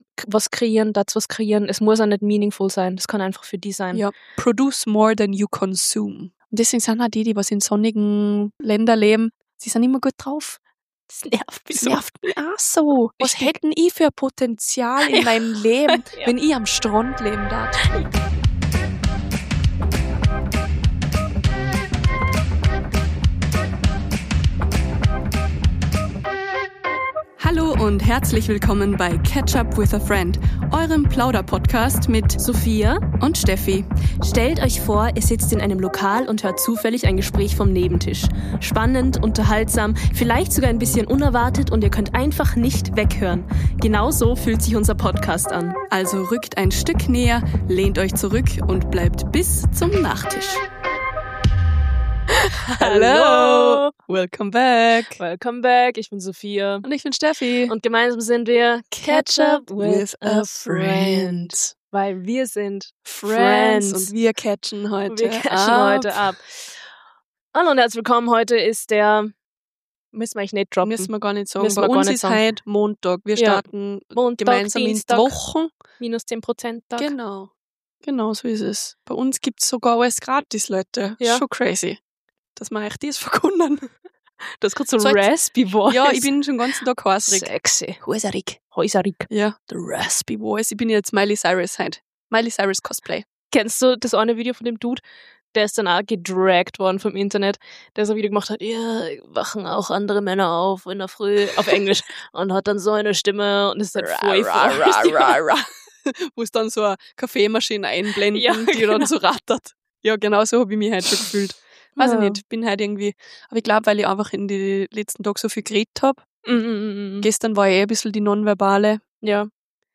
Es geht um Winterblues, Müdigkeit nach den Feiertagen, kleine Strategien zum Durchhalten und warum man nicht immer sofort eine Lösung braucht. Dazwischen: Abschweifungen, Halbwissen, Lachen und ehrliche Gespräche wie am Küchentisch.